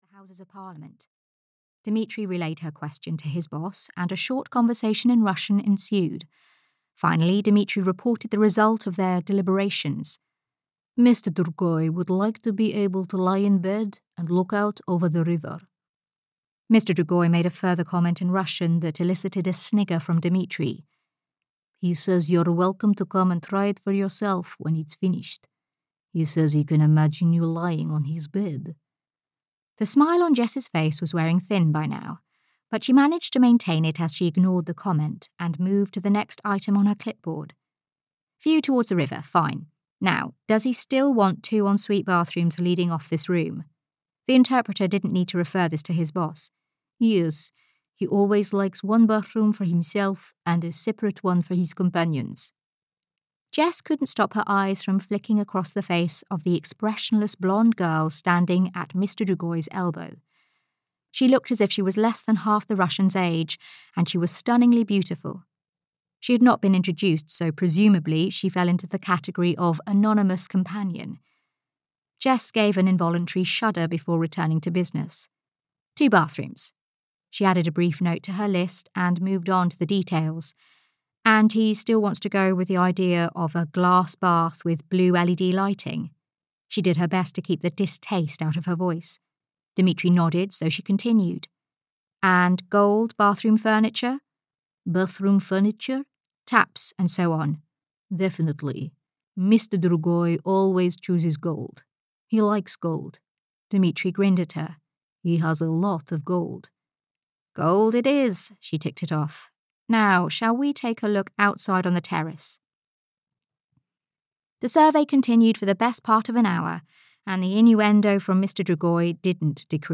Dreaming of St-Tropez (EN) audiokniha
Ukázka z knihy